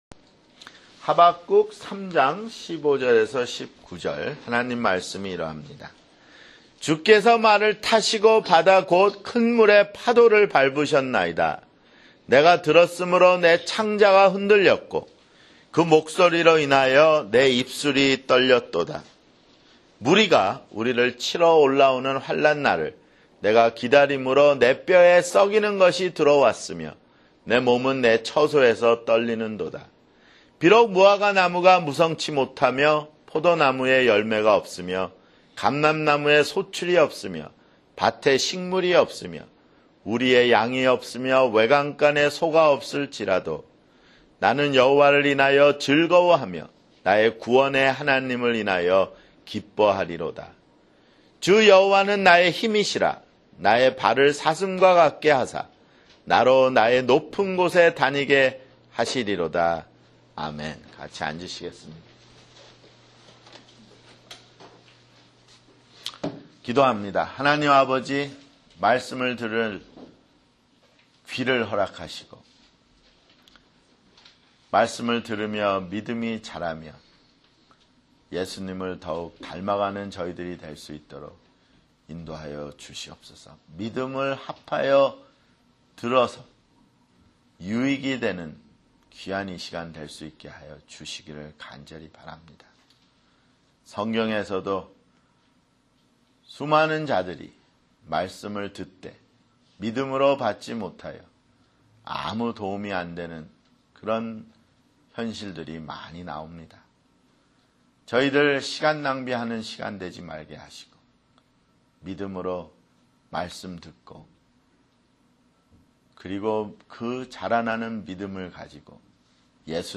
[주일설교] 하박국 (15)